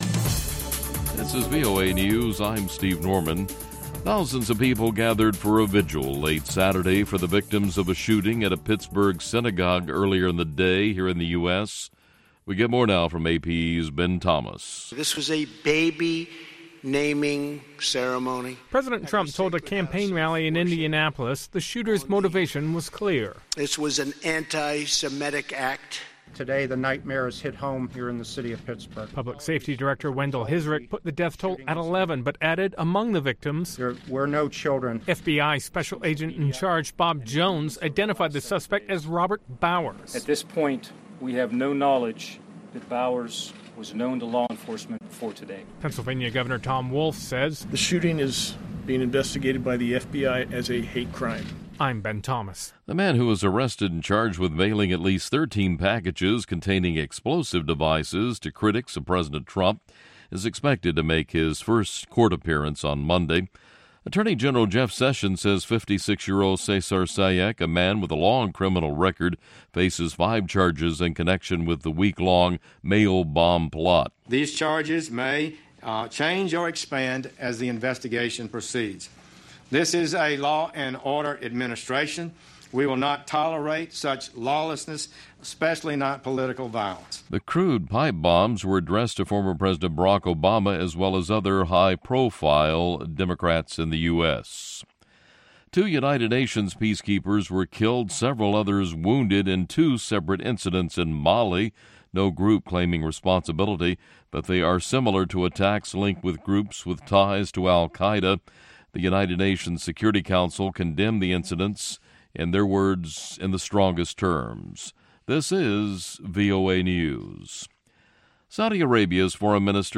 This episode brings you music from ten African countries plus one from the South Pacific Diaspora. It features two in-depth and on-the-ground interviews from Tamale, Ghana and Nairobi, Kenya.